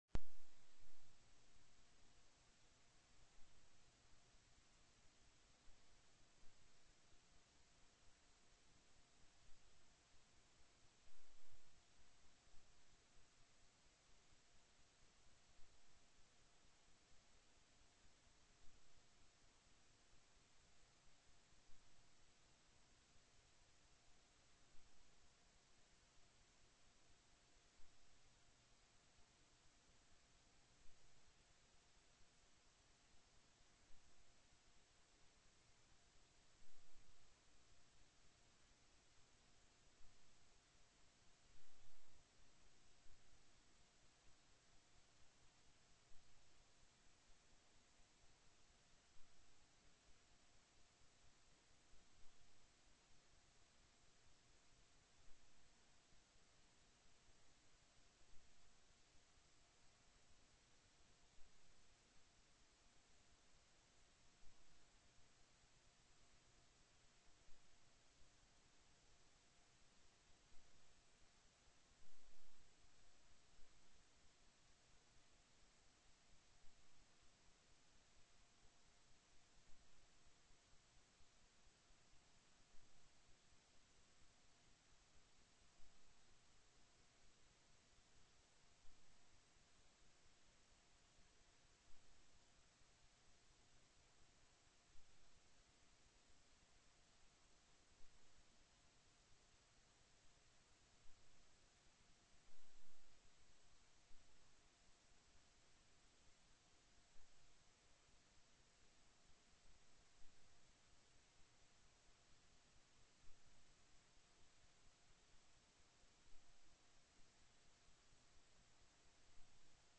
02/04/2009 09:00 AM Senate FINANCE
Co-Chair Hoffman called the Senate Finance Committee meeting to order at 9:03 a.m.